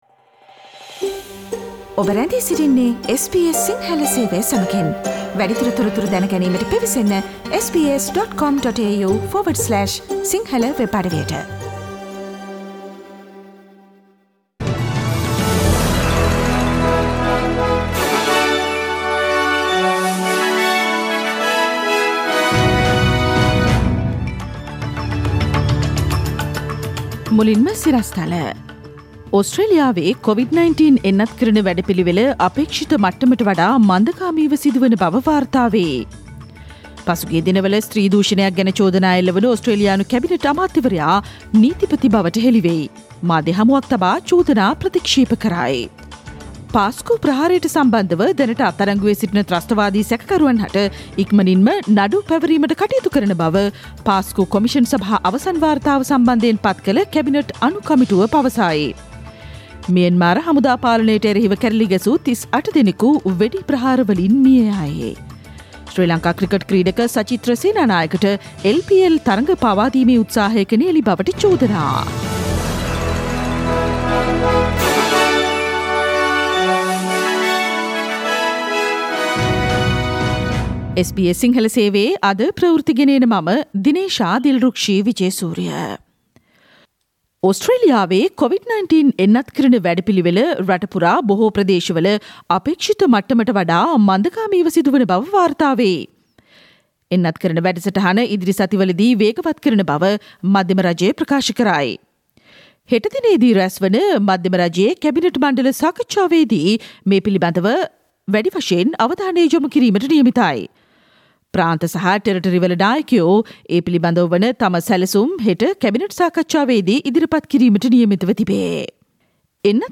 The most prominent news highlights from SBS Sinhala radio daily news bulletin on Tuesday 4 March 2021.